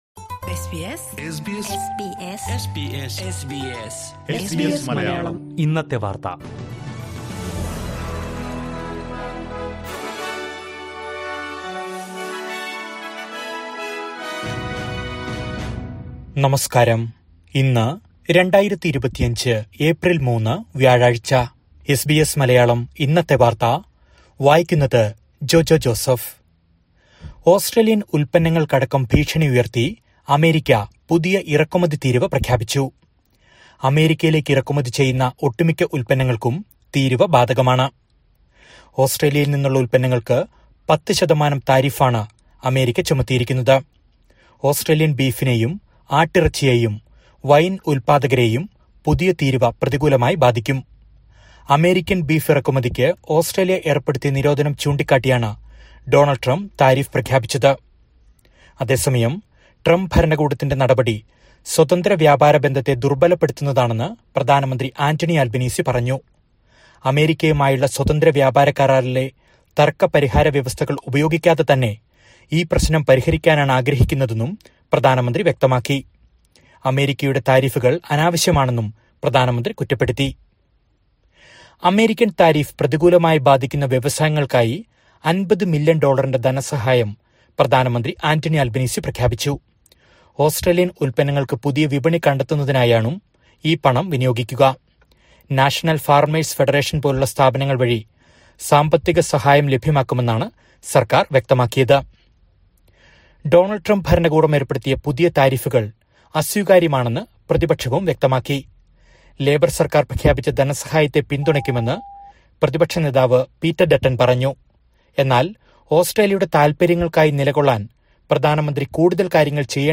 2025 ഏപ്രിൽ മൂന്നിലെ ഓസ്‌ട്രേലിയയിലെ ഏറ്റവും പ്രധാന വാര്‍ത്തകള്‍ കേള്‍ക്കാം...